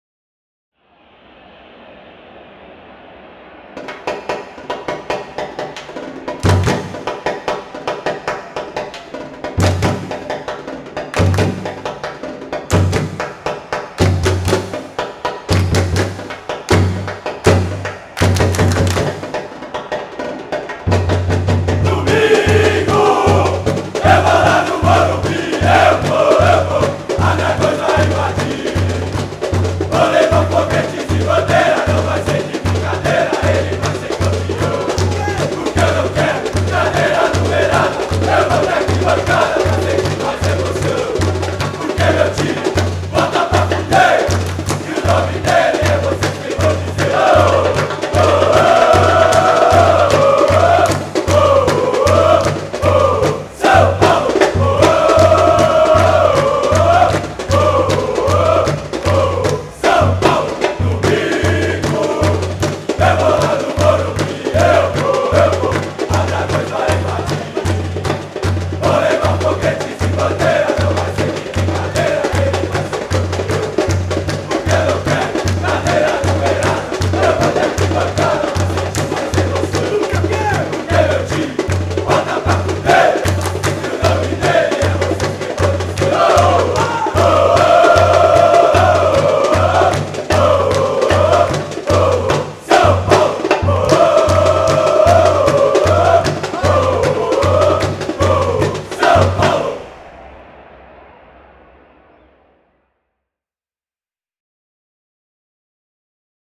Grito da Torcida 2500 kb MP3